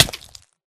hurtflesh2.ogg